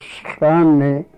Woman
woman.mp3